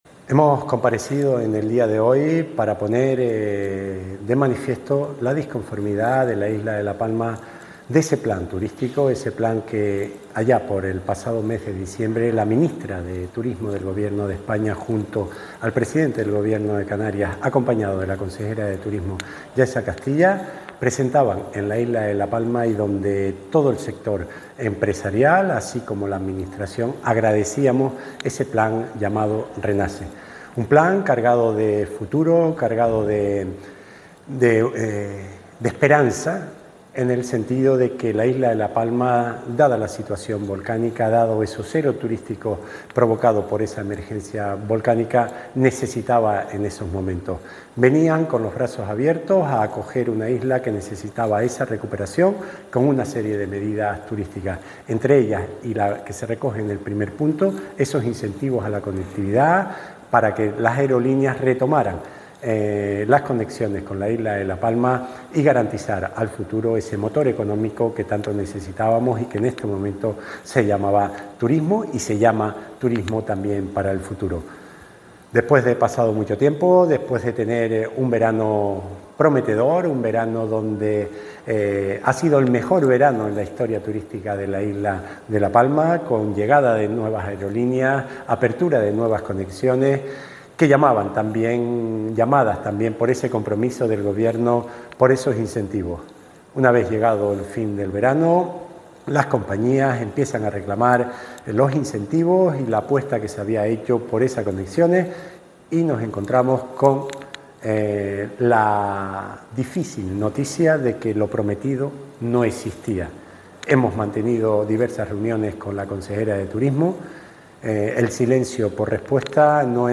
En una comparecencia pública, el consejero recordó que tanto la ministra Reyes Maroto, como la consejera del Gobierno de Canarias, Yaiza Castilla, anunciaron conjuntamente un paquete de medidas dotado con 27,3 millones de euros para apoyar a los autónomos y a las pymes, especialmente a las afectadas por el volcán, y contribuir a la promoción turística de la Isla Bonita.
Declaraciones audio Raúl Camacho_1.mp3